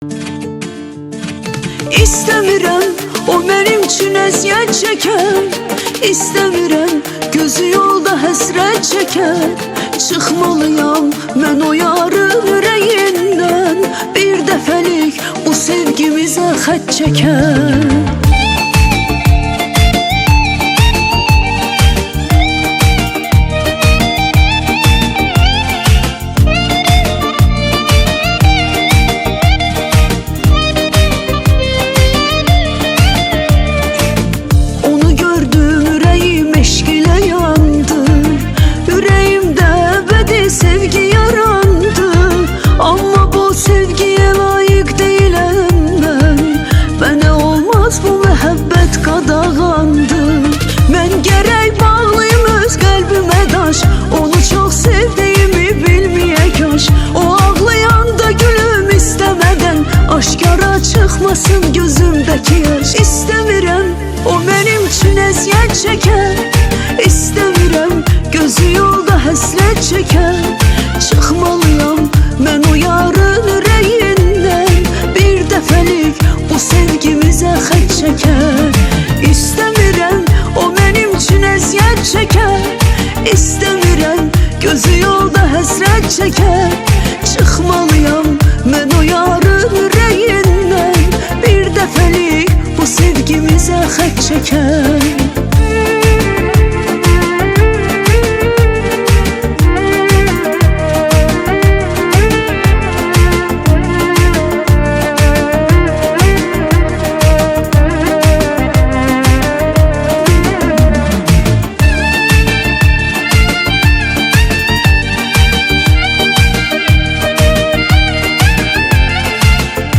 Azeri Muzik